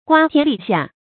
guā tián lǐ xià
瓜田李下发音
成语正音 瓜，不能读作“zhuǎ”。